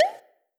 emojiPopSound2.wav